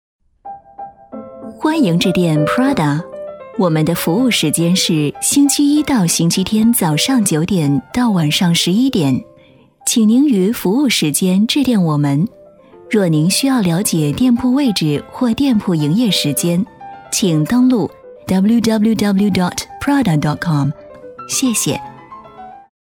女150-电话彩铃
女150-中英双语 成熟知性
女150-电话彩铃.mp3